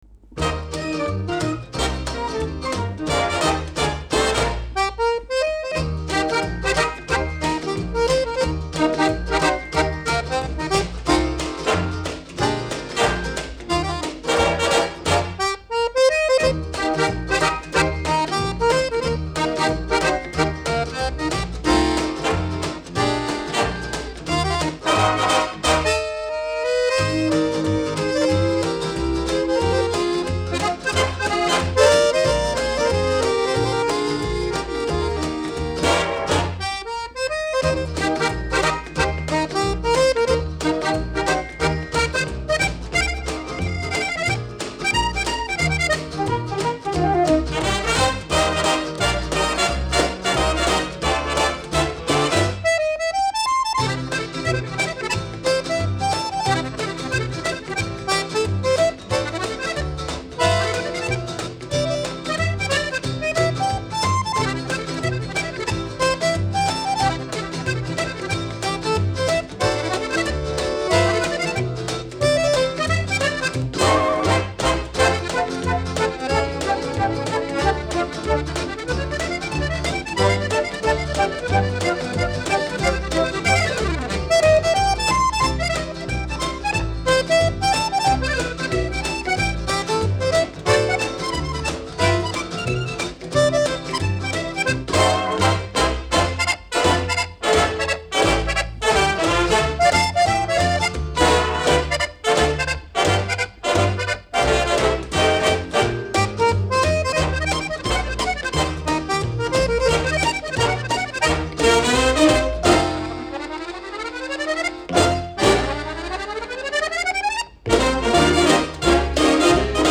12首浪漫柔美的旋律，使人心旷神怡陶醉其中。